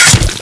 katana_stab.wav